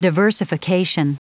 Prononciation du mot diversification en anglais (fichier audio)